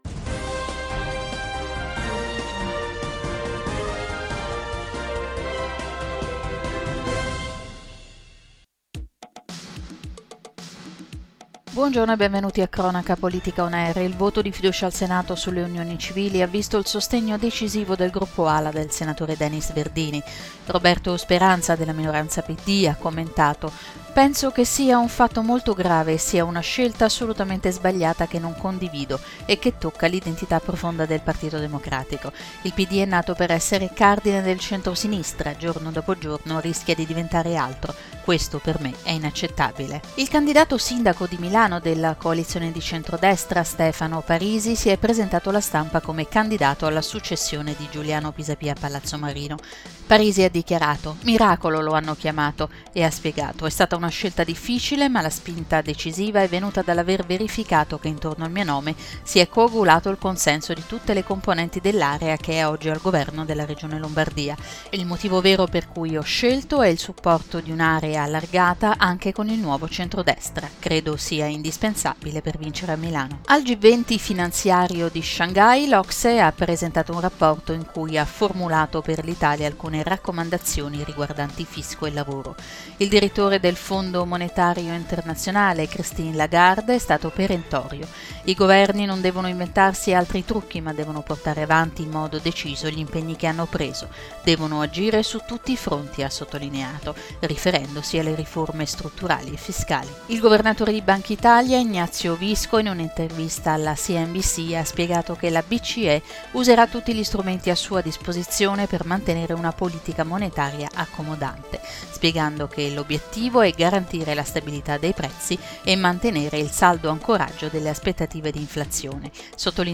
Notiziario 26/02/2016 - Cronaca politica